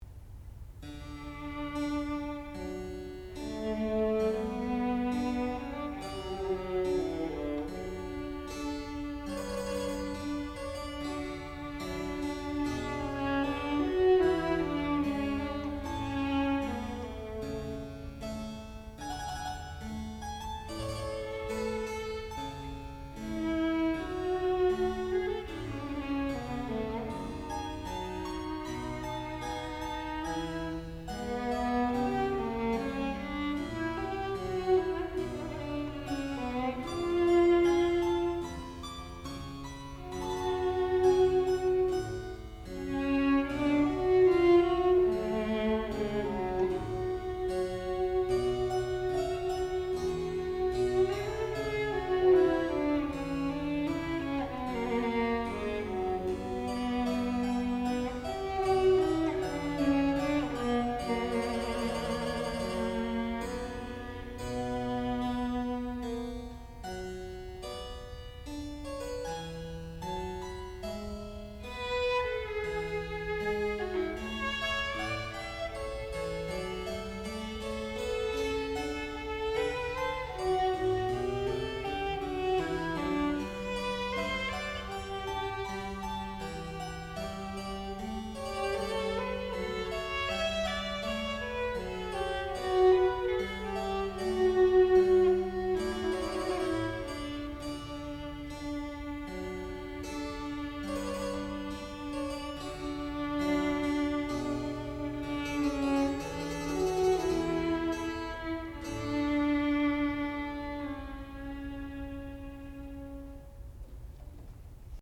sound recording-musical
classical music
harpsichord
Master's Recital
viola